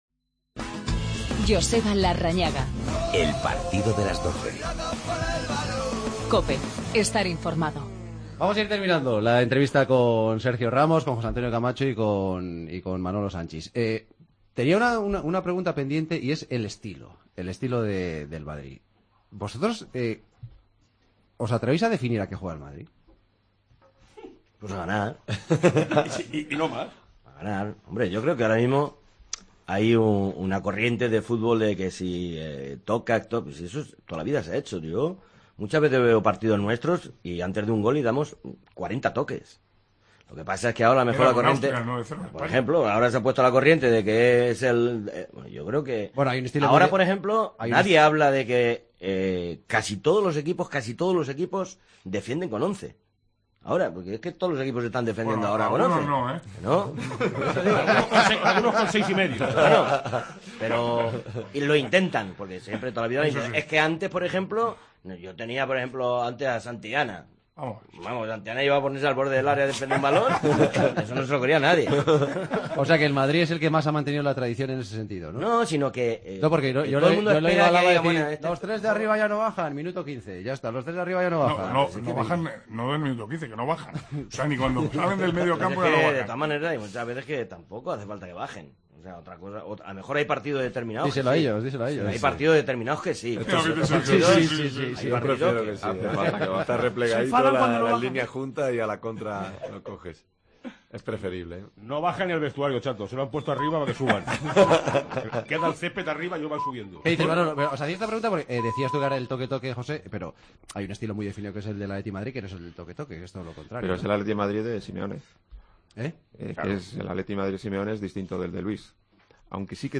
AUDIO: Segunda parte de la entrevista a Sergio Ramos.